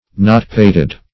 Search Result for " not-pated" : The Collaborative International Dictionary of English v.0.48: Not-pated \Not"-pat`ed\, Nott-pated \Nott"-pat`ed\, a. Same as Nott-headed .
not-pated.mp3